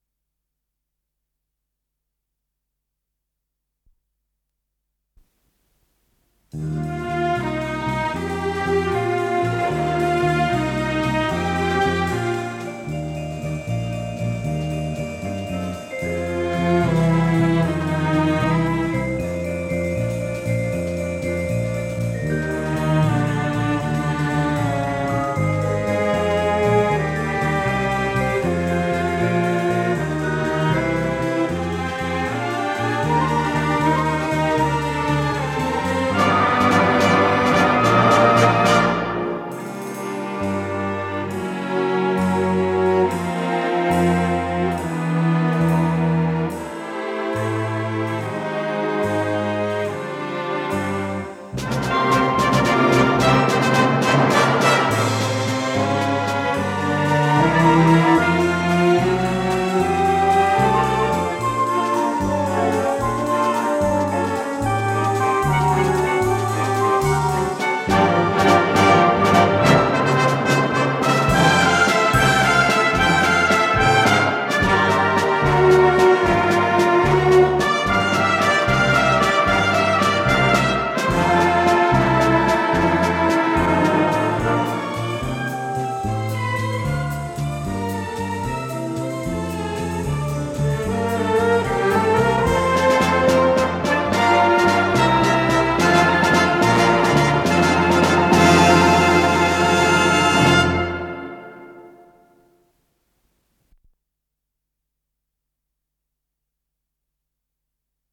ПодзаголовокЗаставка, ми бемоль мажор
Скорость ленты38 см/с
ВариантДубль моно